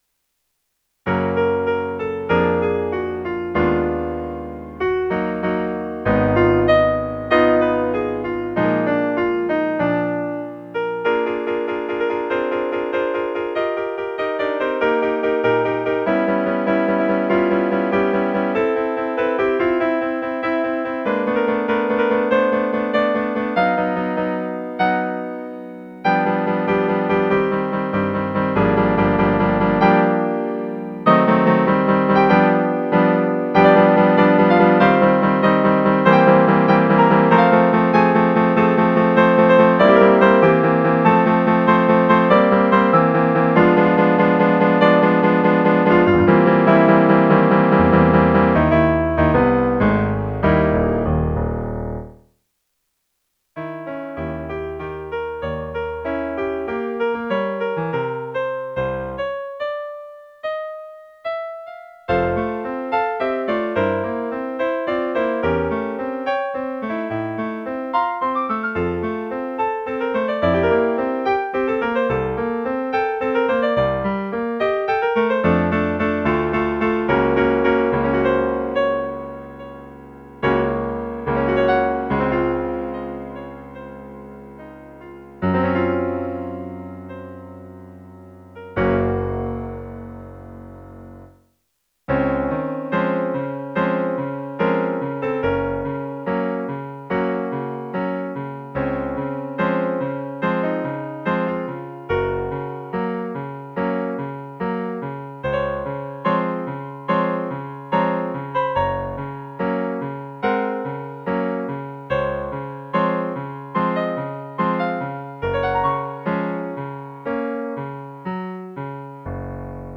''Du buch mit sieben siegeln'' - 피아노 반주